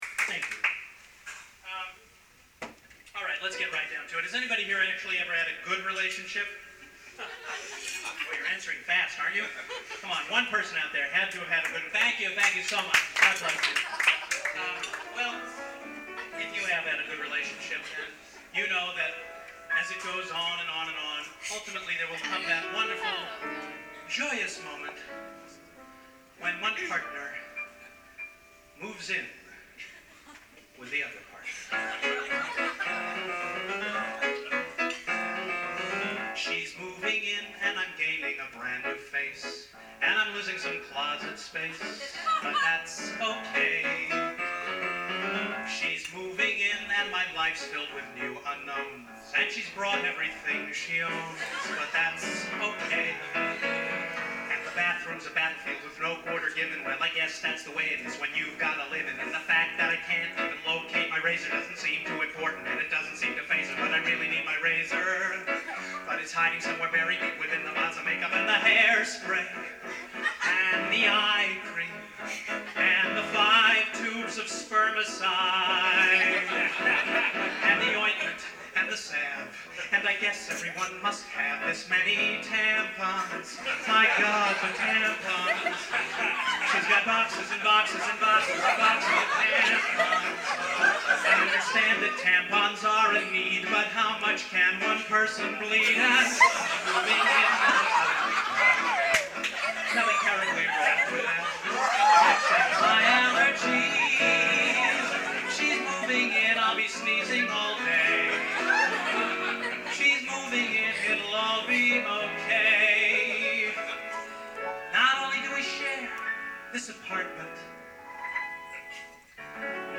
This song I wrote specifically for the act – whether you end up liking it or not, I think you’ll have to agree that it has one huge laugh that drowns out about twenty seconds of what follows – it may well be the biggest laugh any song of mine has ever gotten – I think it’s that I went to a place that no one had gone to and then I went one step beyond.